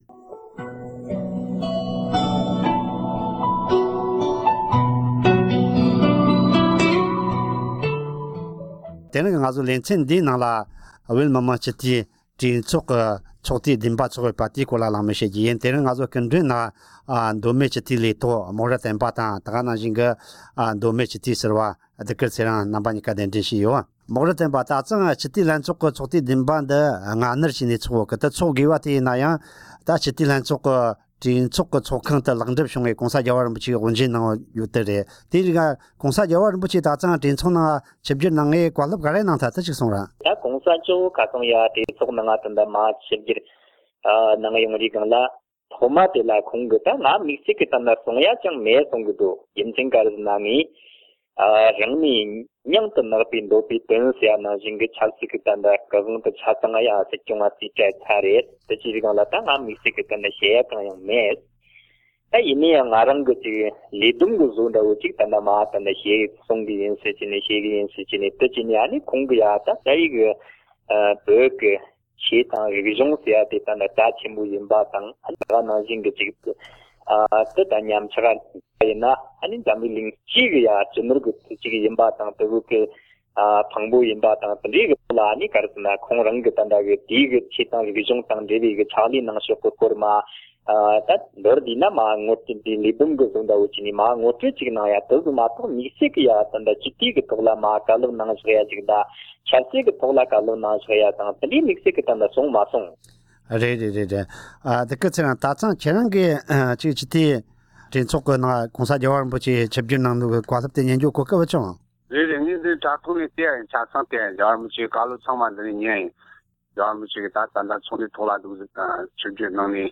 སྐབས་བཅོ་ལྔ་པའི་བོད་མི་མང་སྤྱི་འཐུས་ལྷན་ཚོགས་ཀྱི་གྲོས་ཚོགས་ཚོགས་དུས་བདུན་པ་དང་སྤྱི་འཐུས་ཀྱི་འགན་དབང་སྐོར་གླེང་མོལ།